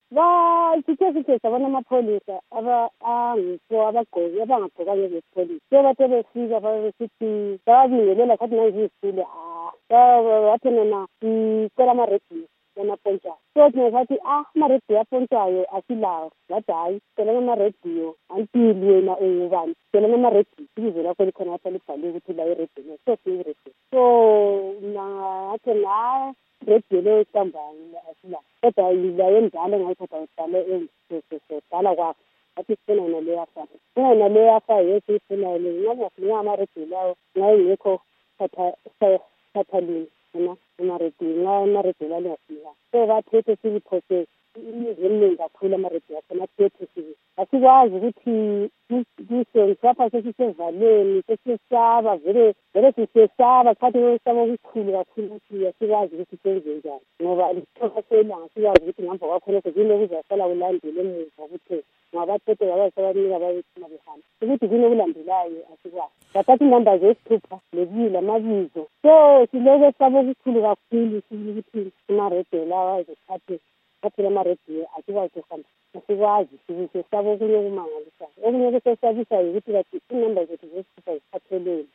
Ingxoxo lesakhamuzi seLupane